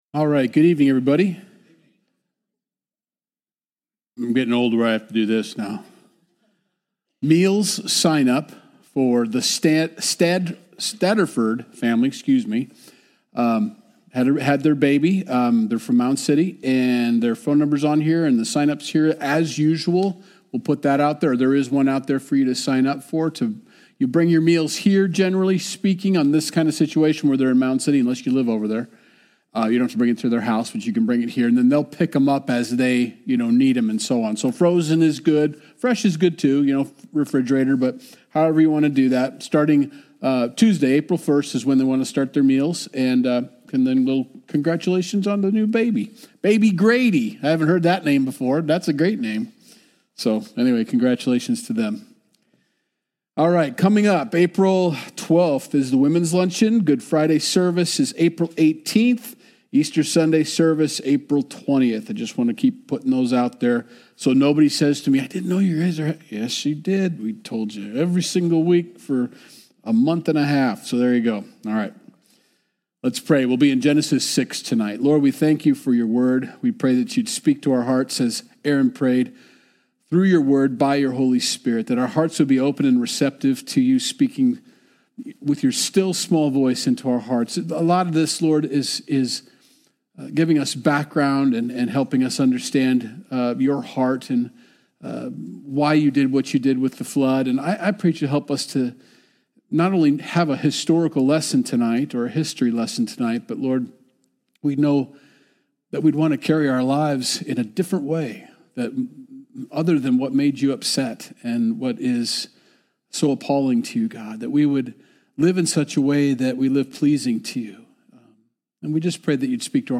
Sunday Message - February 23rd, 2025